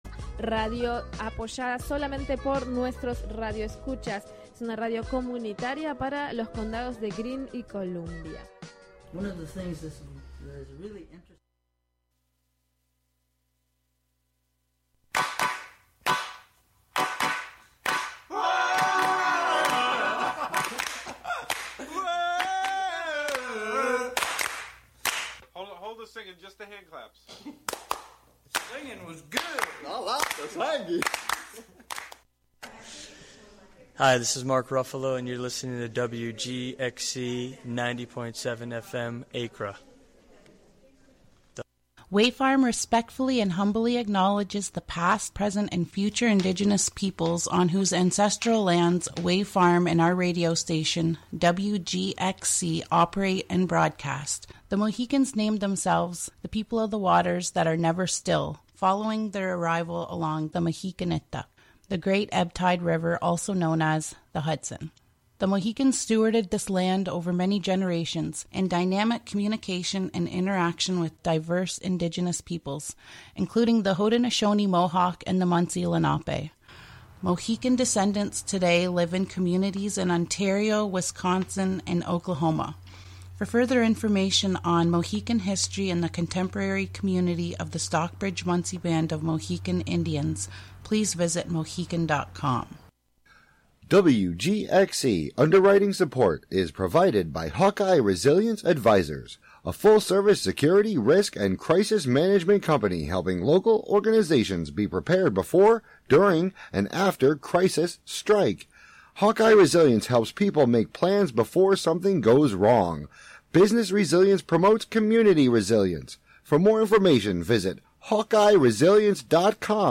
Music, talk, and schtick, just like any variety show.